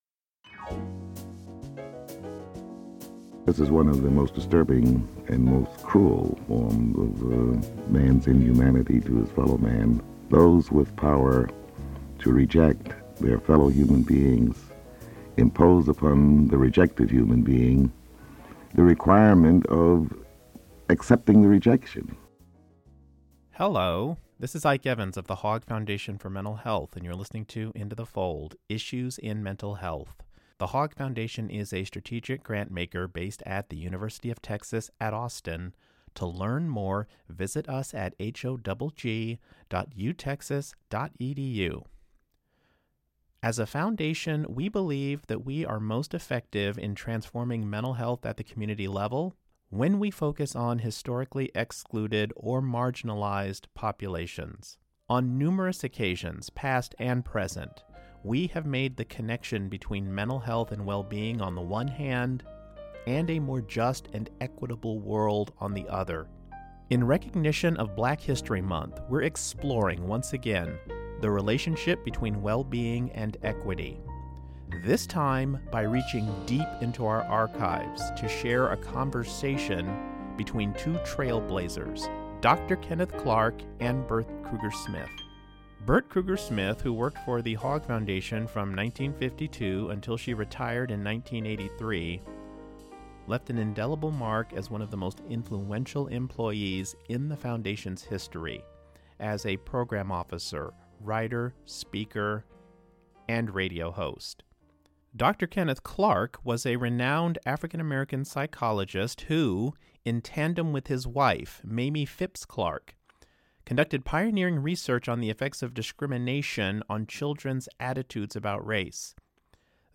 The impact of racism and other forms of inequity on the emotional development of children has been heavily studied over the decades. In recognition of Black History Month, this episode of Into the Fold takes us back into The Human Condition radio show archives with a 1974 broadcast featuring the late African American psychologist Dr. Kenneth Clark, whose innovative research in child psychology—conducted alongside his wife, Mamie Phipps Clark—played a pivotal role in winning the legal battle against segregation in schools. Today, we spotlight his groundbreaking scholarship as well as his advocacy for civil and human rights.